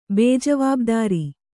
♪ bējavābdāri